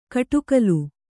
♪ kaṭukalu